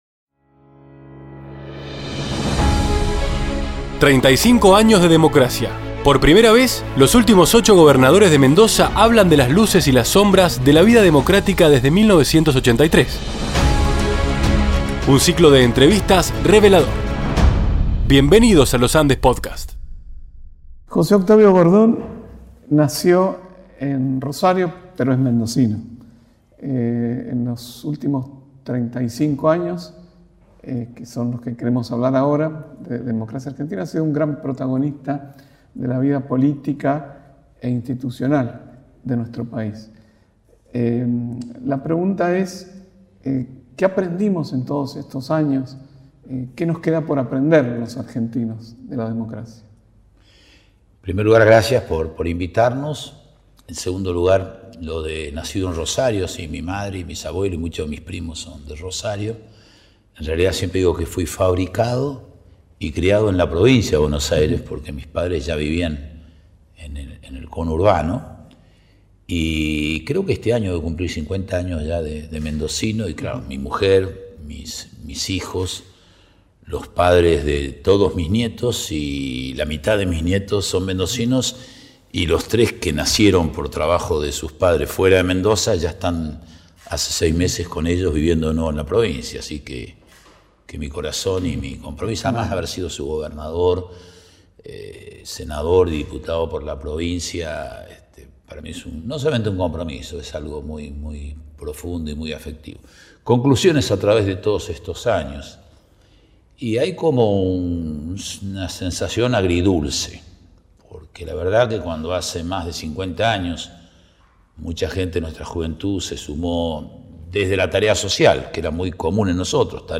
35 AÑOS DE DEMOCRACIA: entrevista a José Octavio Bordón